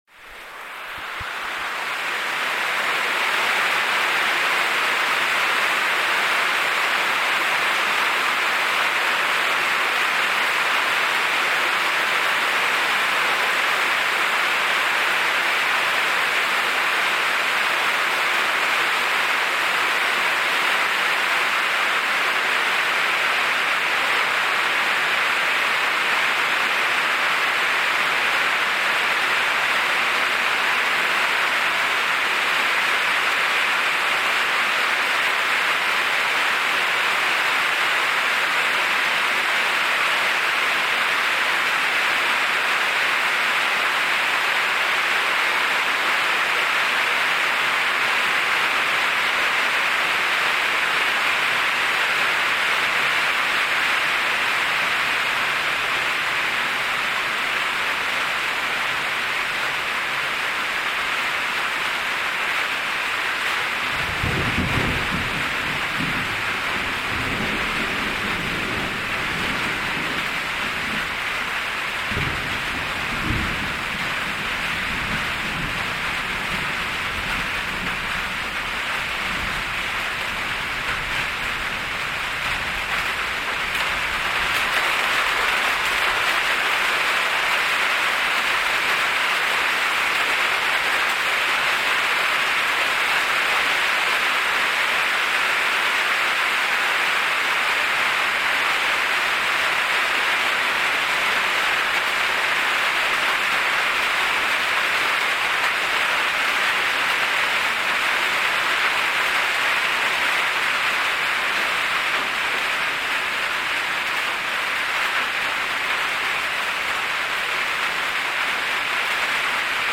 Lluvia
Es de noche y llueve, su presencia invade el universo de una casa con techo de lámina, te invito habitarla a traves del sonido...
Lugar: Tuxtla Gutierrez, Chiapas; Mexico.
Equipo: Grabadora Sony ICD-UX80 Stereo